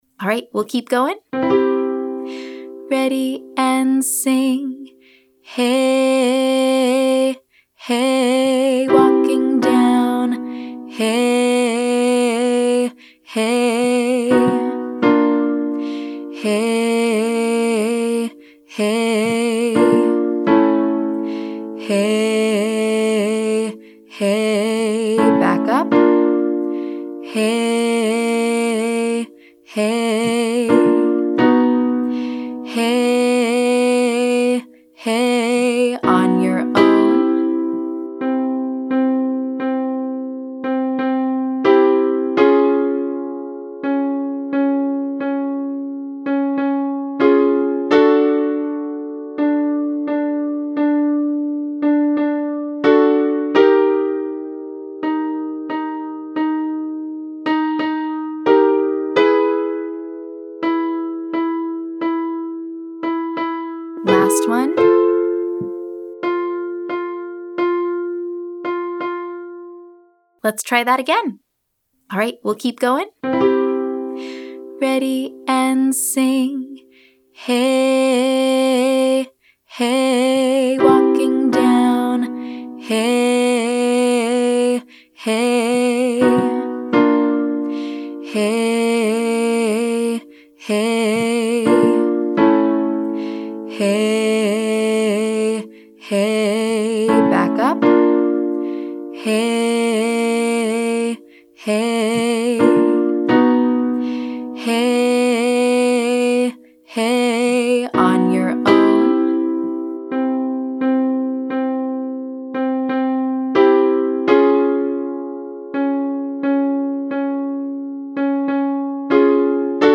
Pulses & Bends - Online Singing Lesson
Exercise: Pulse x3 slow, x1 fast
Exercise: Bend 1x slow, 1x fast